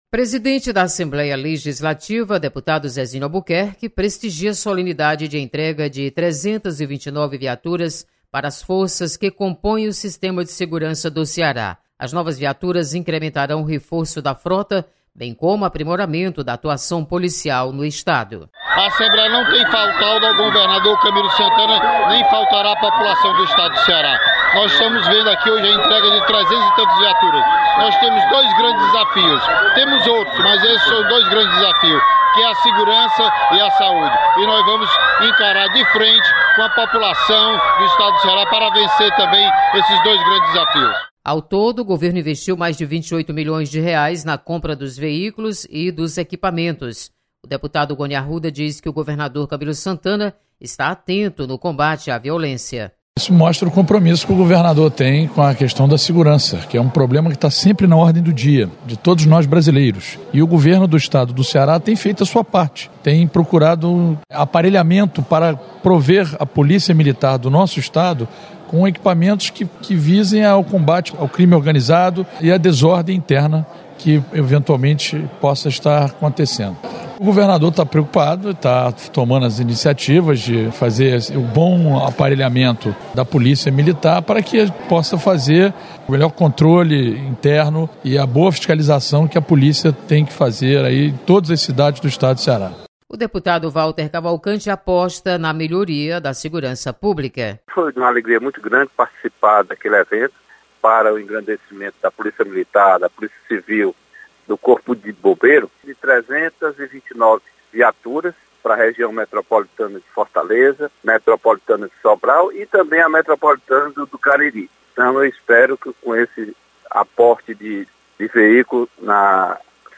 Você está aqui: Início Comunicação Rádio FM Assembleia Notícias Segurança